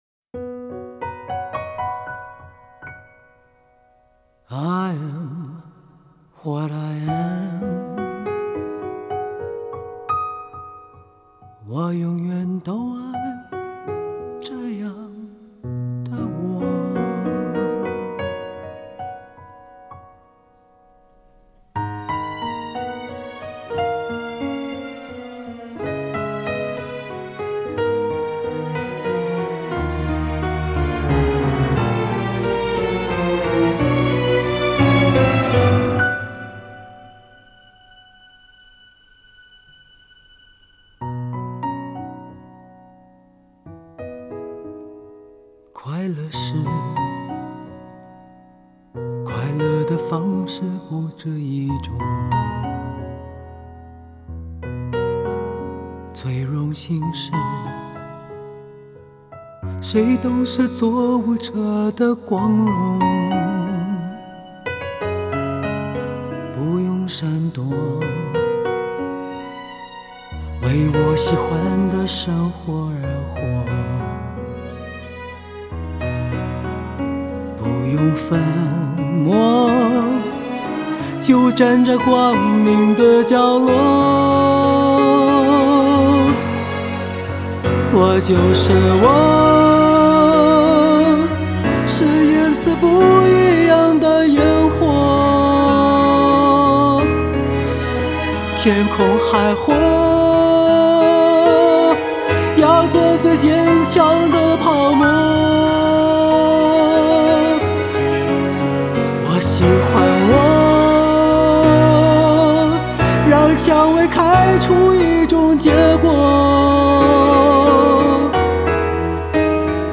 不知是他的音色或是尾音拉长的技巧，听他唱这首歌很享受，即刻能平复情绪。